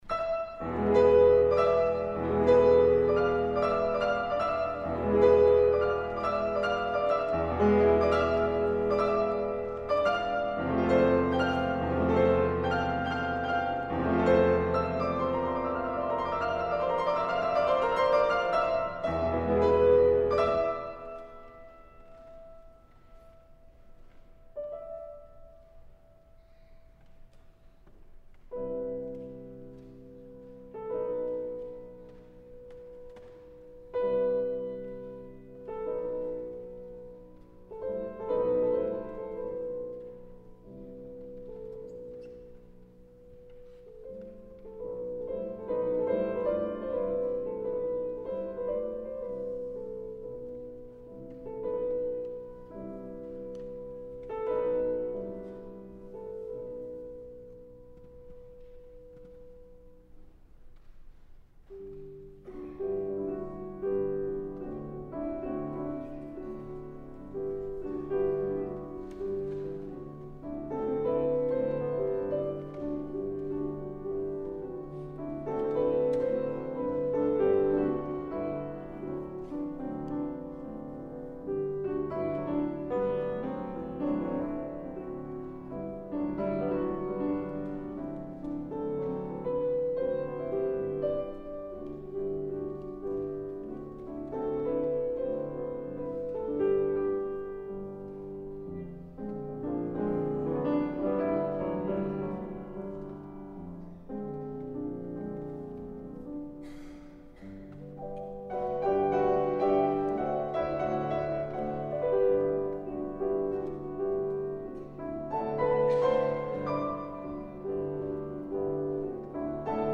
06 Falla El Amor Brujo (piano)
06+Falla+El+Amor+Brujo+-+piano.mp3